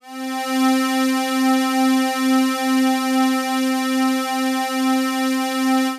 C4_trance_pad_2.wav